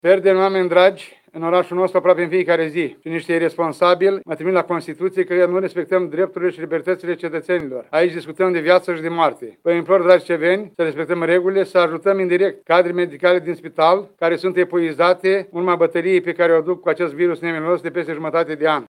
Primarul Ion Lungu face un apel la suceveni să respecte regulile sanitare pentru a salva cât mai multe vieți.